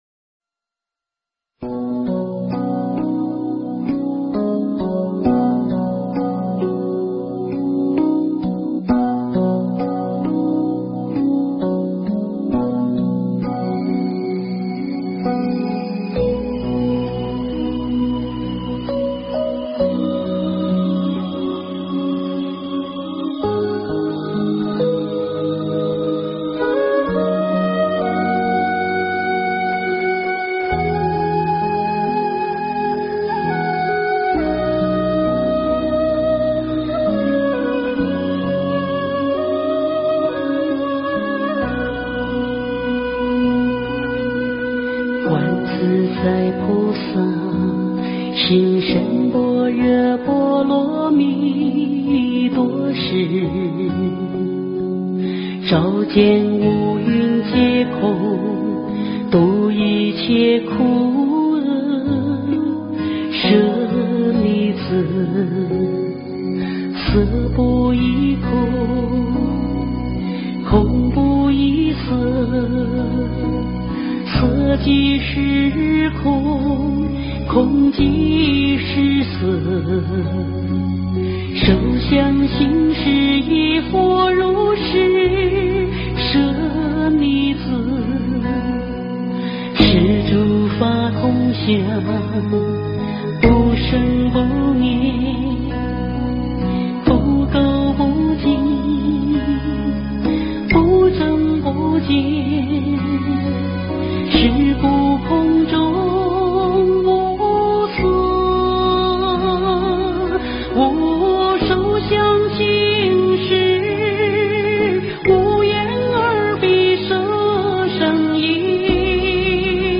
诵经
佛音 诵经 佛教音乐 返回列表 上一篇： 地藏王菩萨赞 下一篇： 般若波罗蜜多心经 相关文章 佛说阿弥陀三耶三佛萨楼佛檀过度人道经A 佛说阿弥陀三耶三佛萨楼佛檀过度人道经A--风柯月渚...